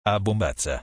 ttsmp3_TamVNyX.mp3